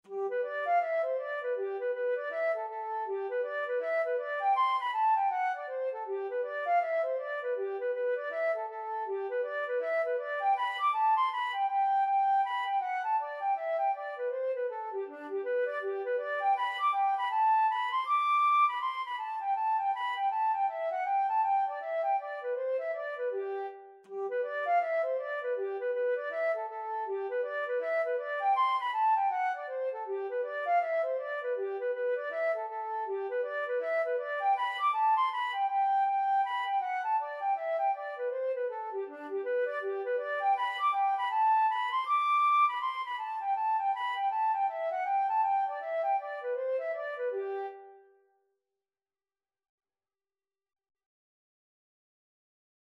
Traditional Trad. Early Rising (Irish Folk Song) Flute version
G major (Sounding Pitch) (View more G major Music for Flute )
4/4 (View more 4/4 Music)
D5-D7
Flute  (View more Easy Flute Music)
Traditional (View more Traditional Flute Music)